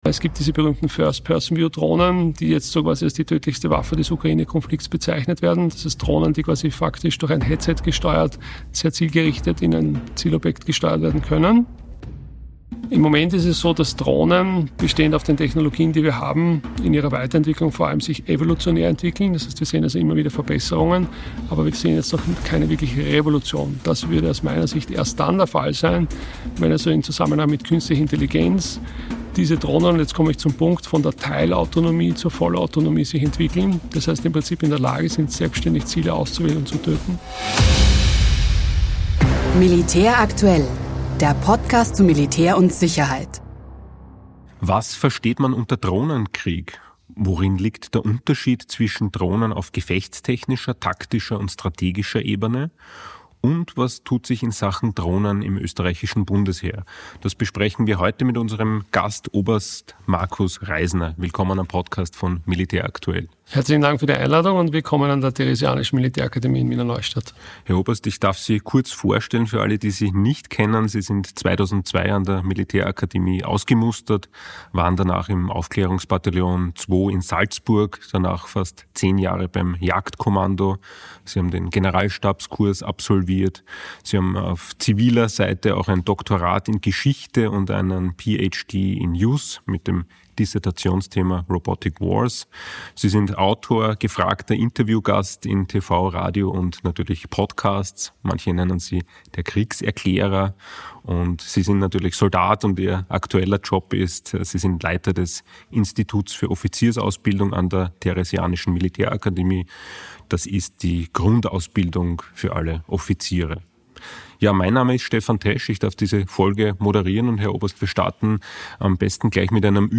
Oberst Markus Reisner im Interview über Drohnen